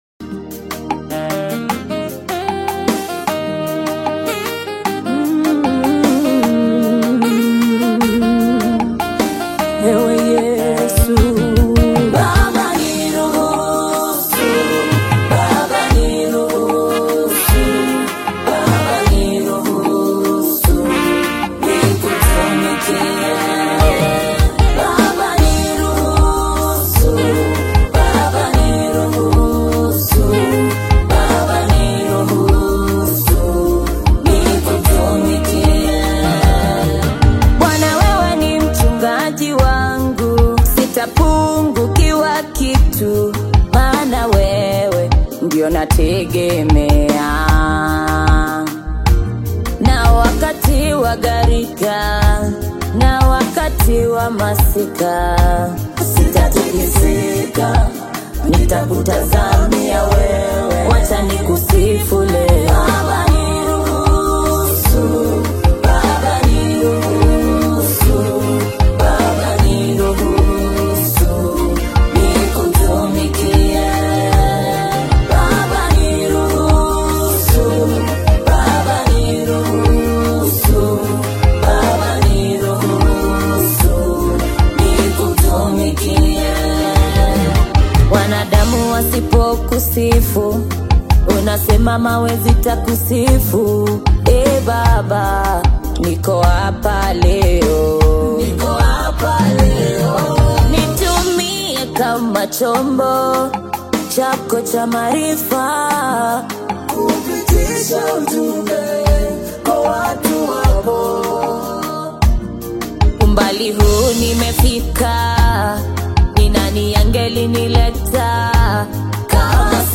stirring Tanzanian gospel single
vocalist
uplifting Swahili praise-driven lyrics
contemporary East African gospel music